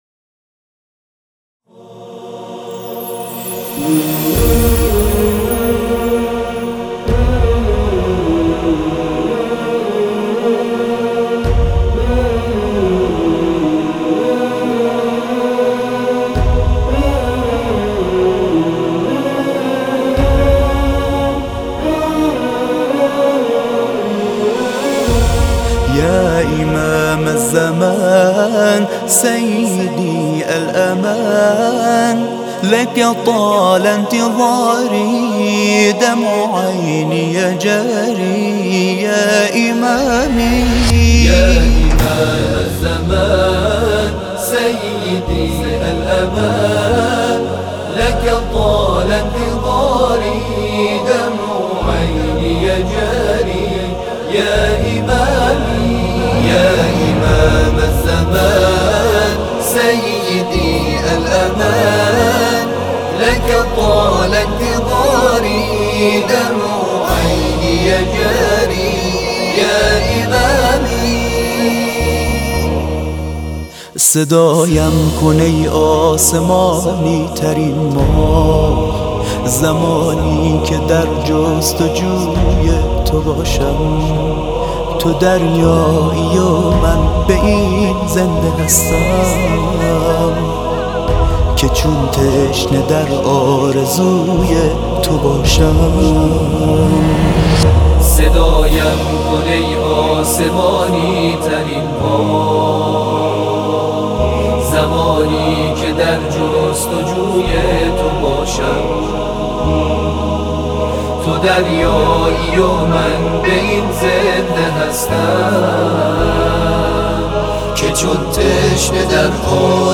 تواشیح